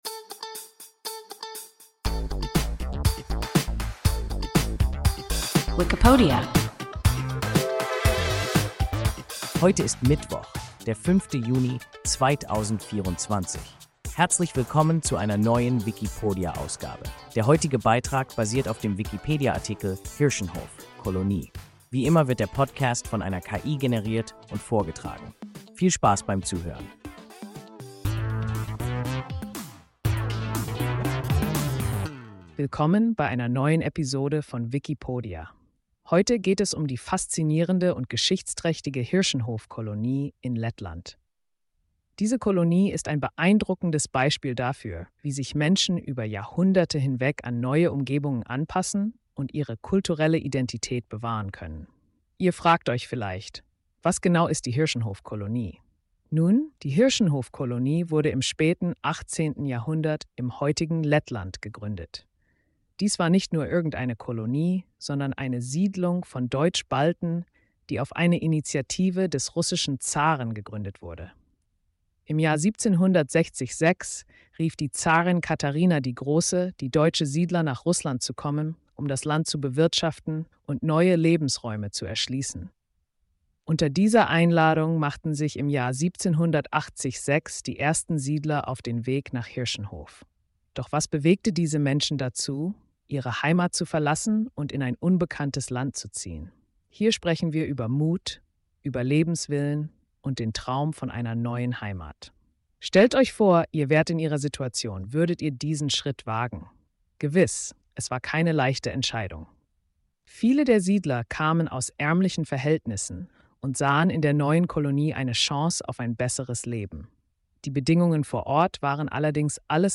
Hirschenhof (Kolonie) – WIKIPODIA – ein KI Podcast